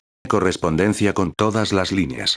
megafonias exteriores